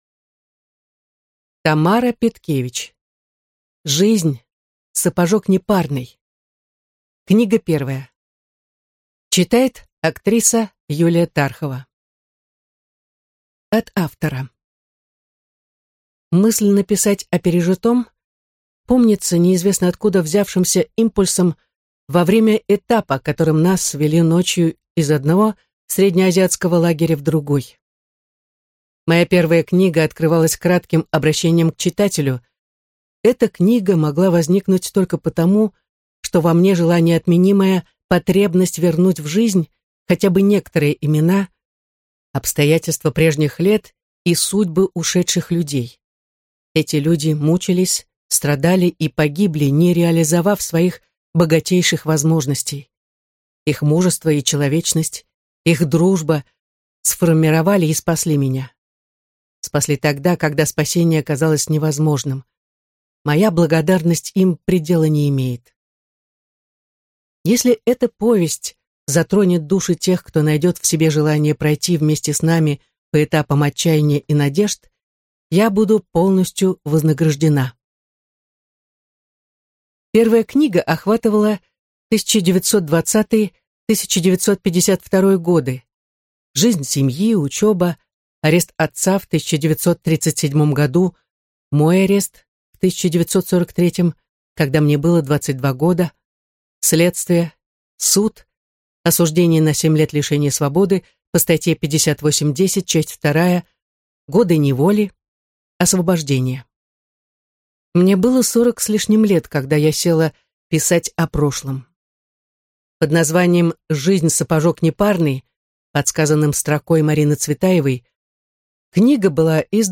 Аудиокнига Жизнь – сапожок непарный. Книга первая | Библиотека аудиокниг